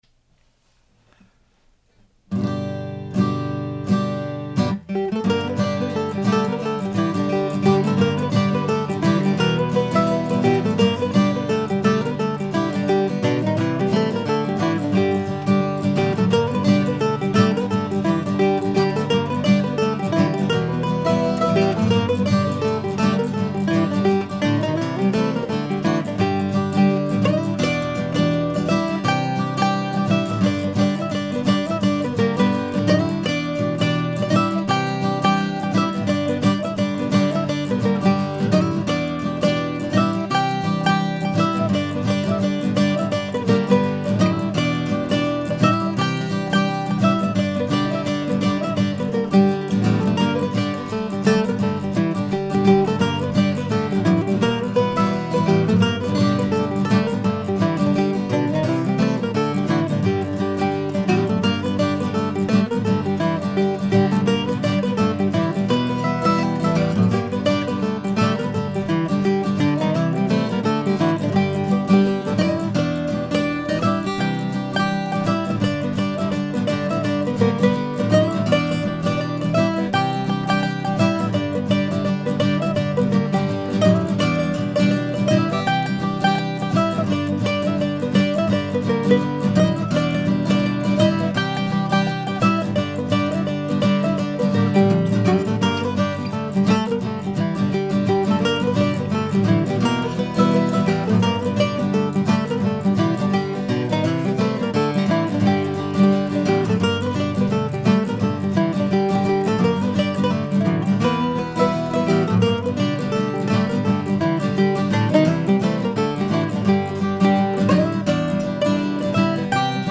I like the recording here but I have to mention that the ending is a bit of a train wreck. I fumbled putting a tag on the original rhythm track and then compounded it by adding competing tags to the additional guitar and mandolin tracks, thinking I would "fix it in the mix". In the end the train wreck sounds more fun, so I left them all on.